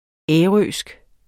ærøsk adjektiv Bøjning -, -e Udtale [ ˈεːˌʁøˀsg ] Betydninger 1. fra eller vedr.